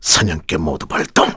Crow-Vox_Skill5-2_kr.wav